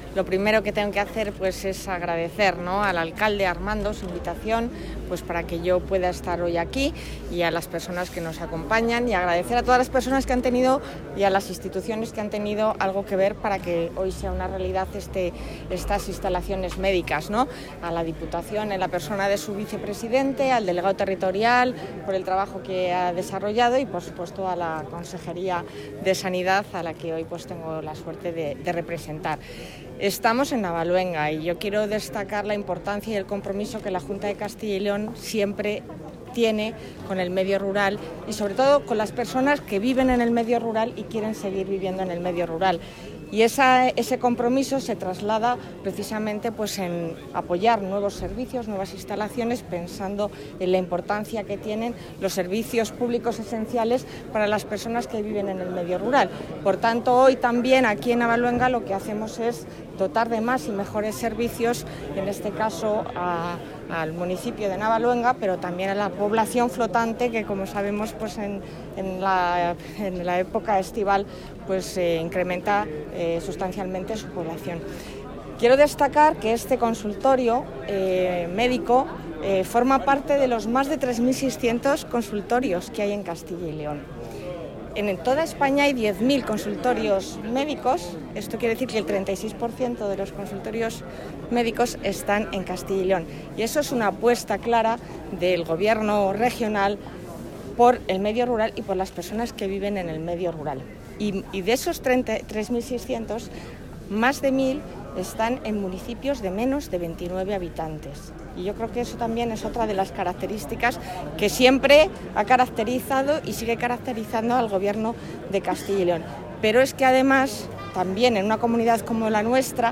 La consejera de Familia e Igualdad de Oportunidades, Alicia García, ha asistido a la inauguración de un consultorio médico en la...
Declaraciones de la consejera de Familia e Igualdad de Oportunidades.